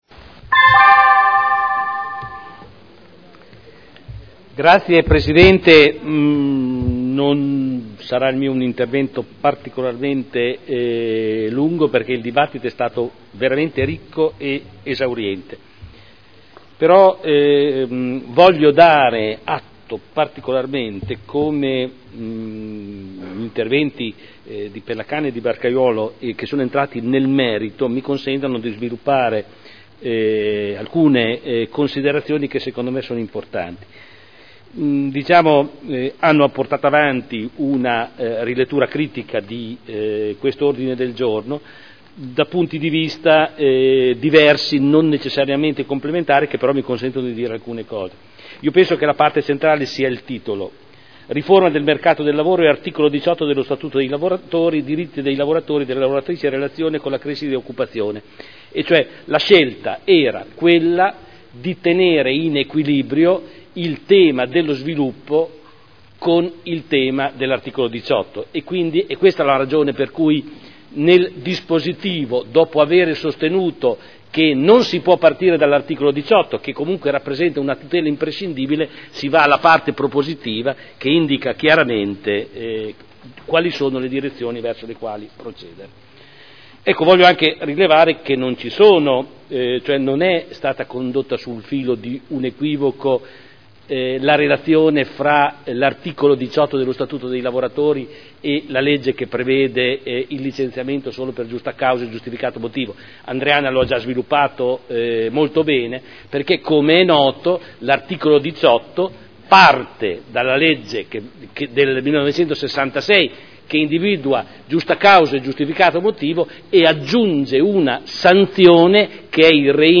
Seduta del 27 febbraio. Mozione presentata dai consiglieri Ricci (Sinistra per Modena) e Trande (P.D.) avente per oggetto: “Riforma del “mercato del lavoro” e Articolo 18 dello Statuto dei Lavoratori: diritti dei lavoratori, delle lavoratrici e relazione con la crescita e occupazione” Dibattito